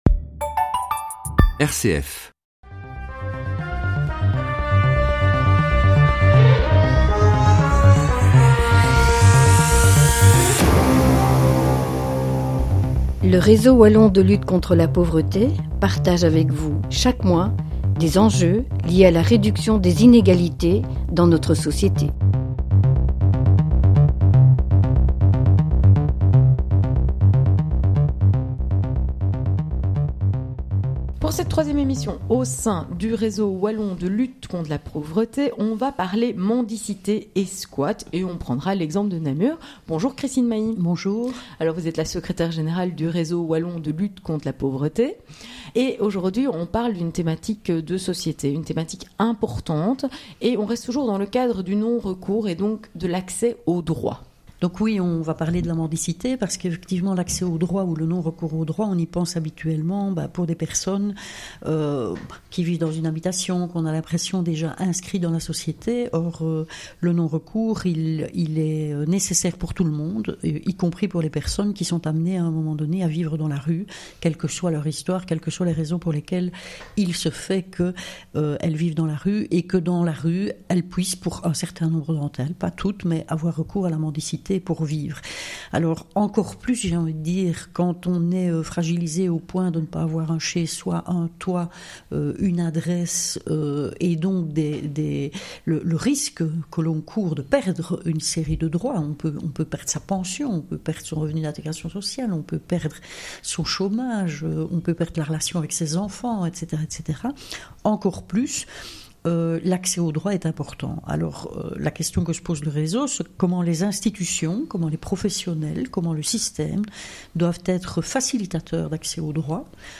Emissions radio avec RCF